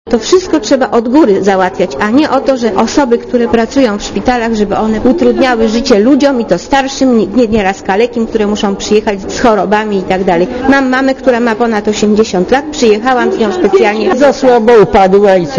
Mówią pacjenci warszawskiego szpitala